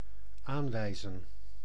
Ääntäminen
IPA: /ˈanʋɛɪzə(n)/